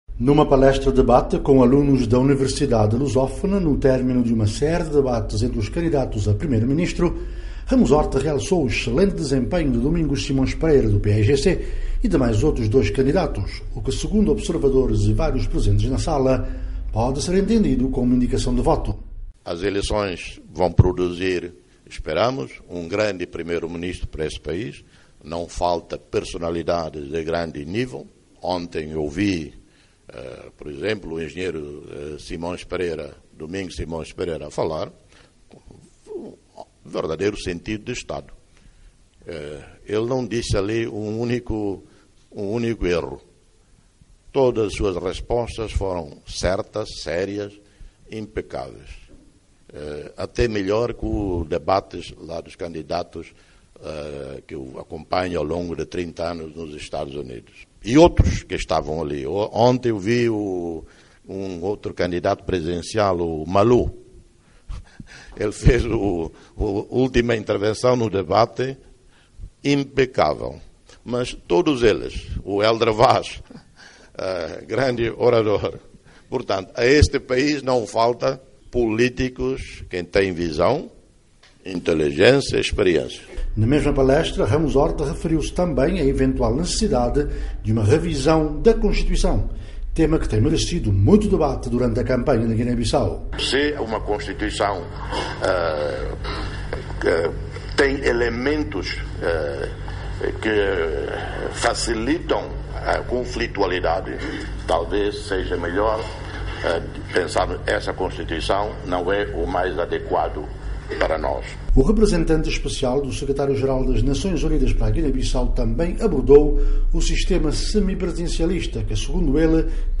Ramos Horta fala à VOA sobre o escrutínio de 13 de Abril - 2:53